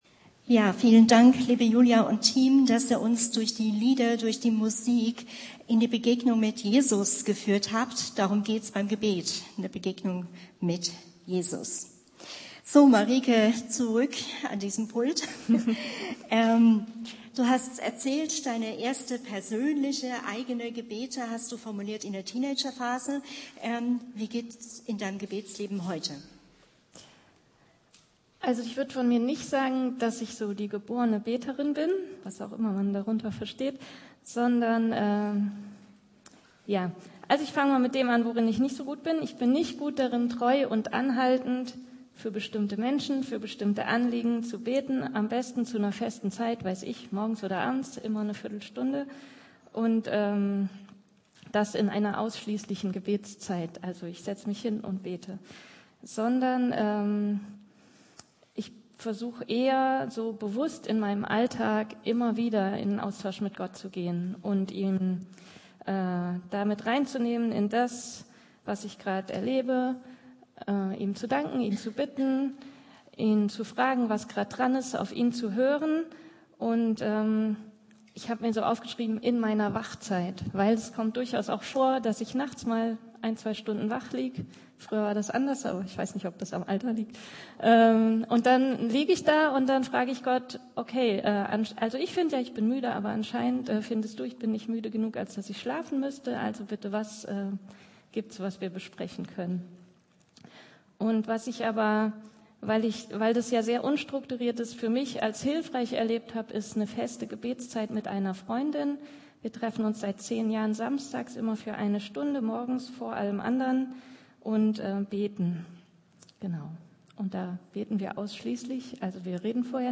Juni 2025 Predigt Mit dem Laden des Videos akzeptieren Sie die Datenschutzerklärung von YouTube.
Ein Interview